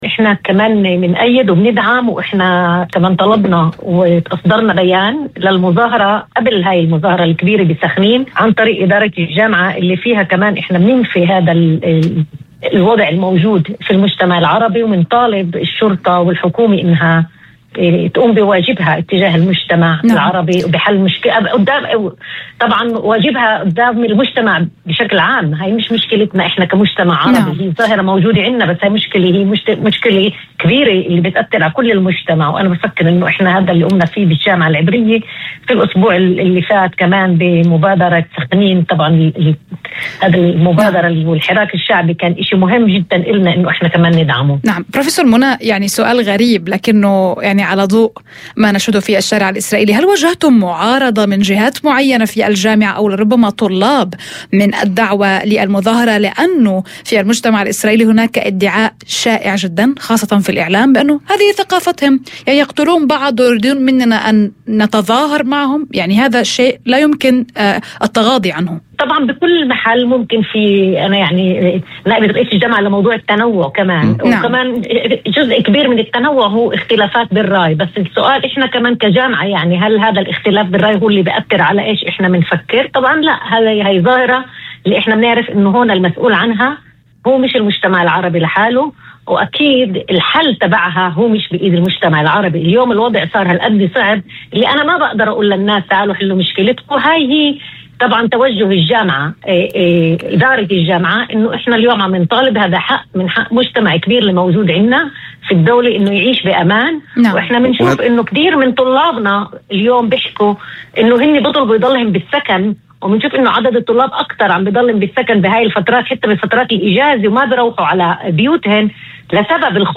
وأضافت في مداخلة هاتفية لبرنامج "يوم جديد"، على إذاعة الشمس، أن هذه الظاهرة لا تخص المجتمع العربي وحده، بل تمثل مشكلة مجتمعية عامة تقع مسؤولية معالجتها على عاتق الدولة ومؤسساتها.